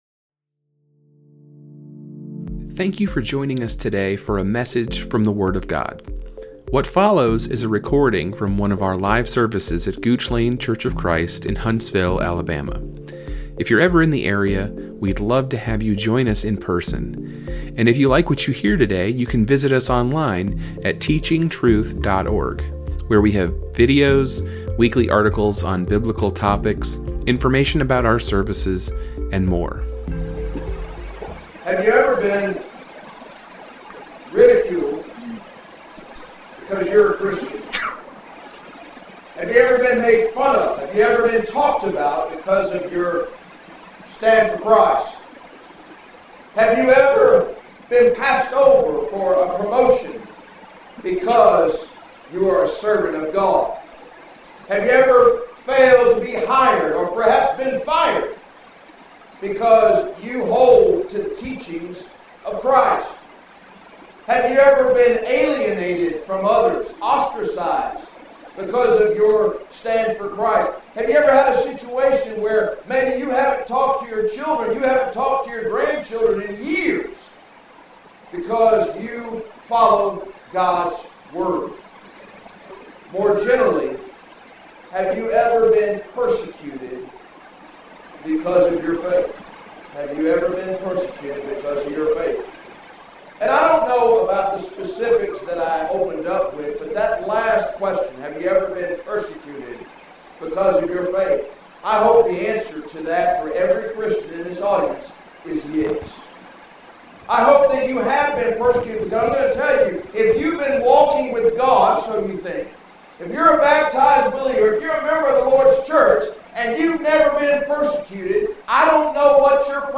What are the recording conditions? The second of four lessons in our Gospel Meeting